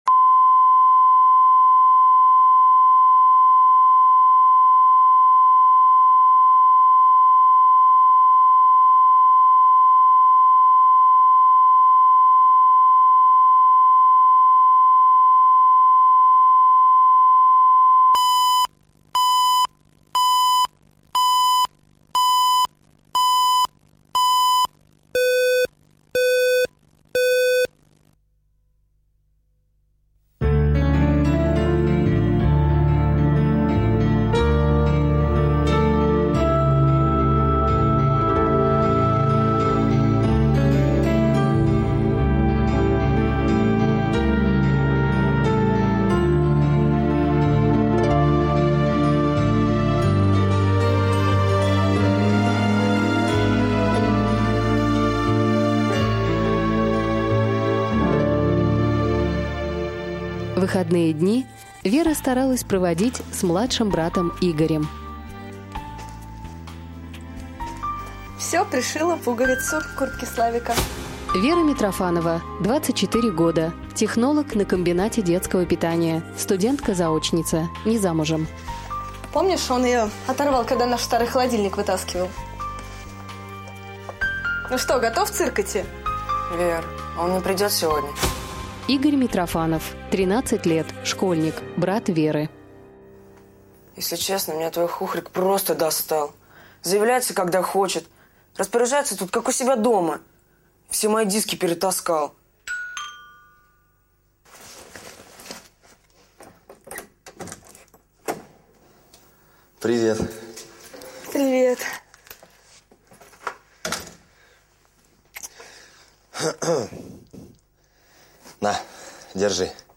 Аудиокнига Турнир